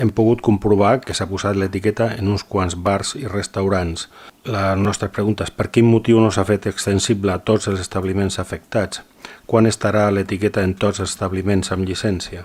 El portaveu dels comuns, Sebastián Tejada, actualitzava dijous passat en quin punt es troba el desplegament de la norma, assegurant que encara no s’ha completat.